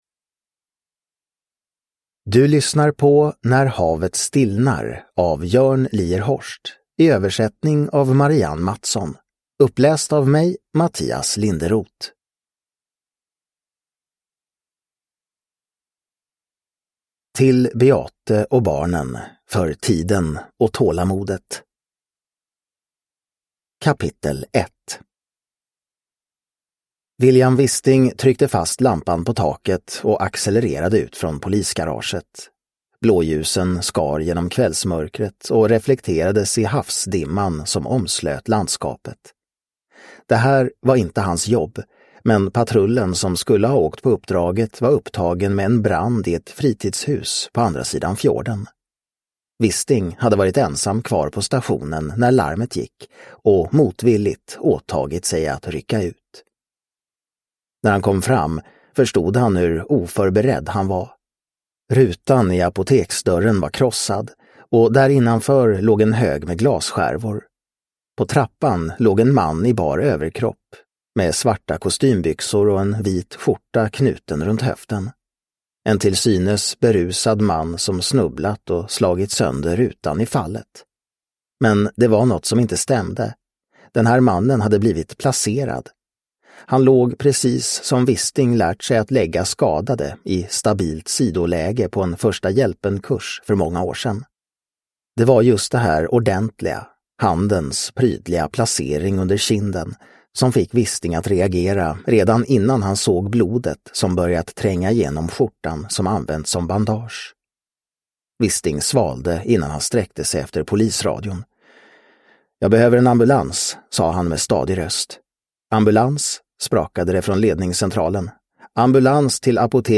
När havet stillnar – Ljudbok – Laddas ner